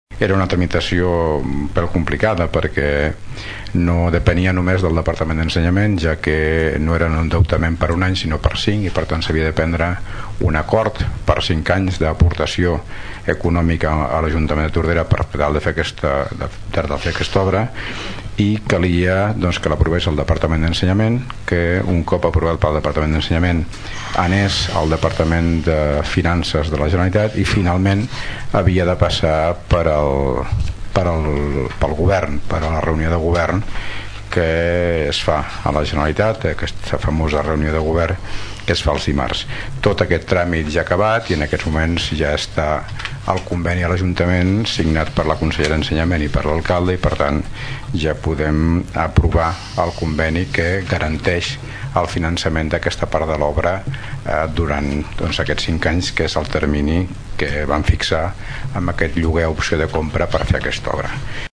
L’alcalde de Tordera, Joan Carles Garcia va explicar que el conveni garanteix el finançament de l’obra.